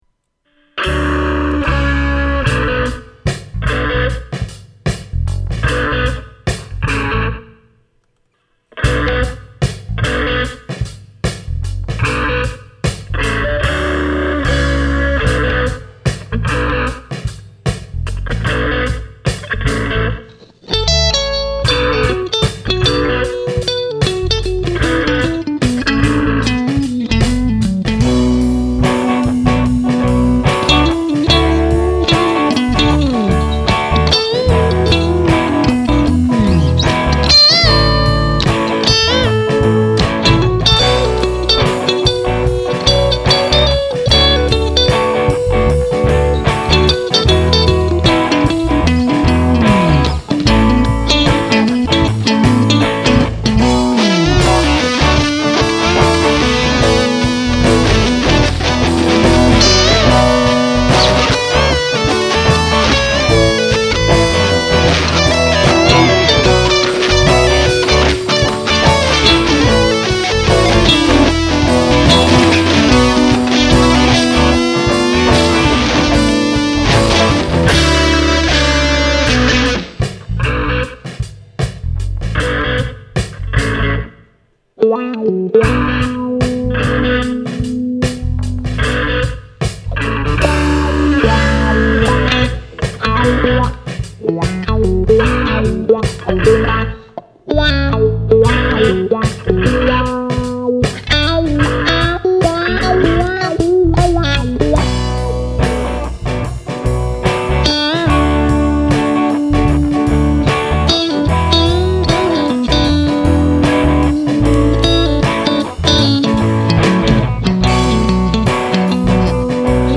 download // Instrumental // 4.2mb
instrumental.mp3